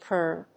/kɝn(米国英語), kɜ:n(英国英語)/